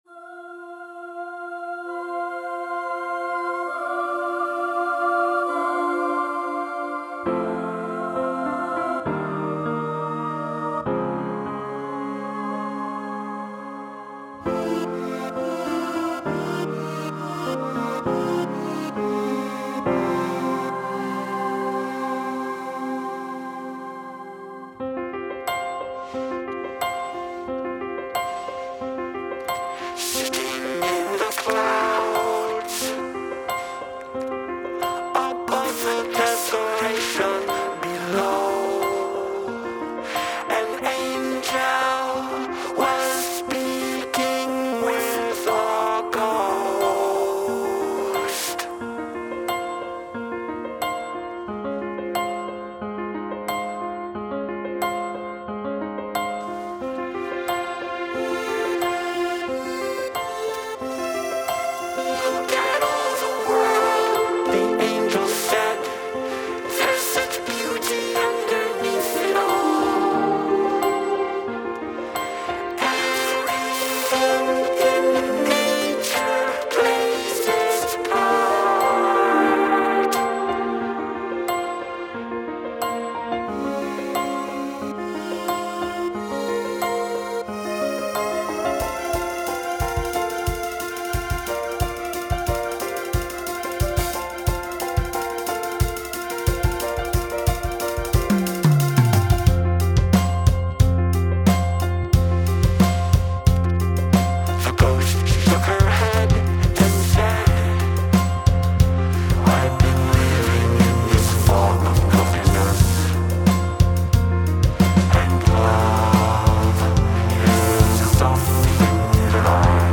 haunting beauty